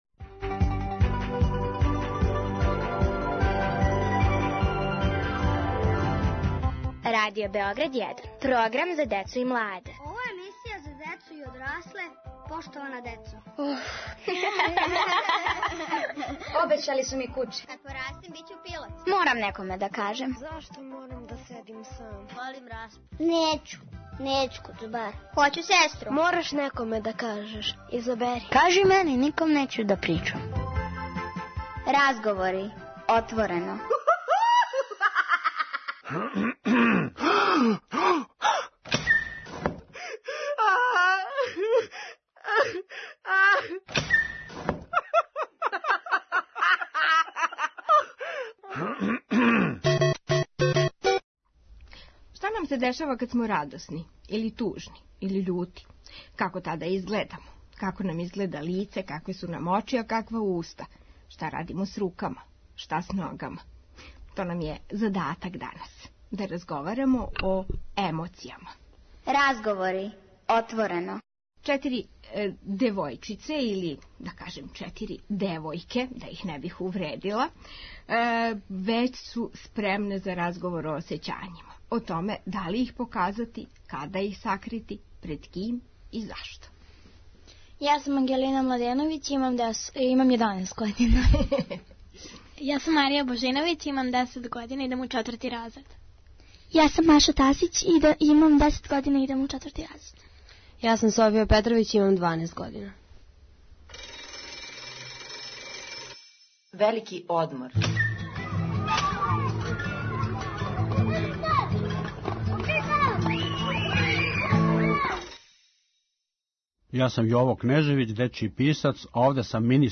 Поштована деца и поштовани одрасли од поверења разговарају о осећањима.